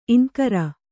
♪ iŋkara